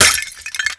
ceramic_impact2.wav